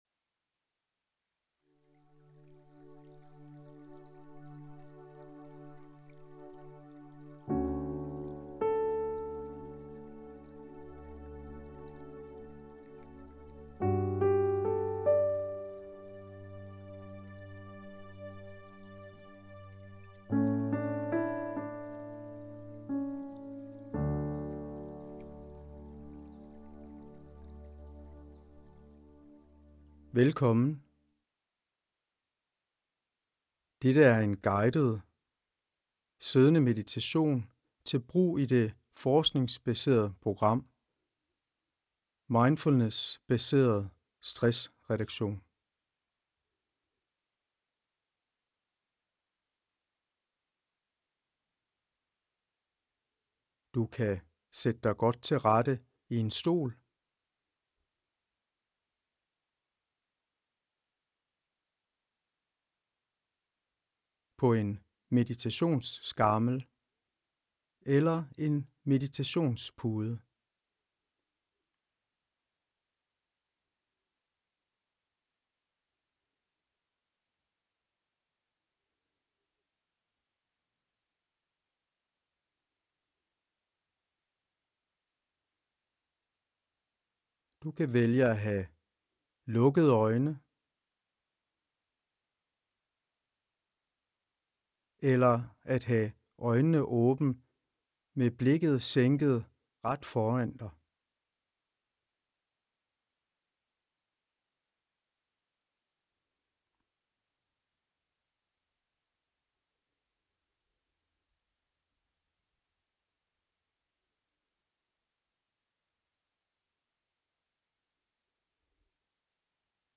Guidet siddende meditation